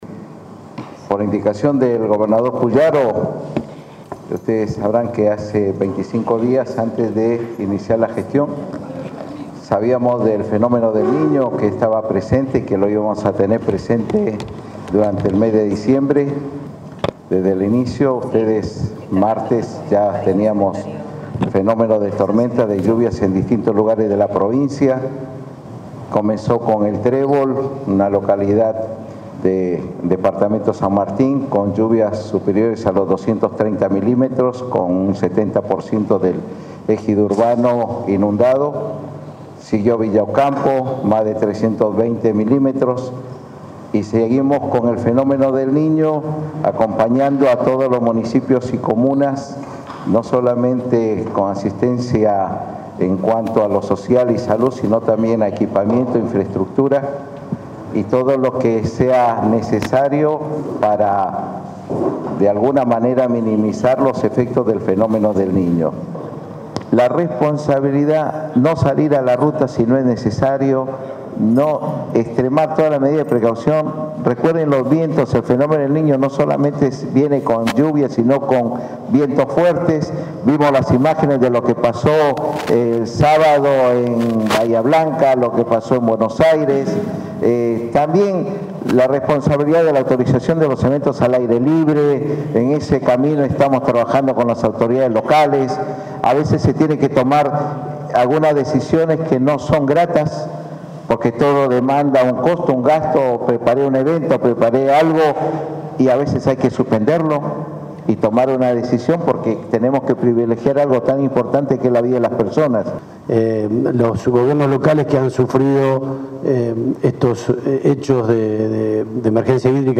De la conferencia desarrollada este lunes en Casa de Gobierno participaron el secretario de Protección Civil de la Provincia, Marcos Escajadillo; los intendentes de Santa Fe, Juan Pablo Poletti, y de Santo Tomé, Miguel Weiss Ackerley; el senador por el Departamento La Capital, Paco Garibaldi; y el secretario de Gobierno, Municipios y Comunas, Horacio Ciancio.
Emergencia hídrica - Conferencia de prensa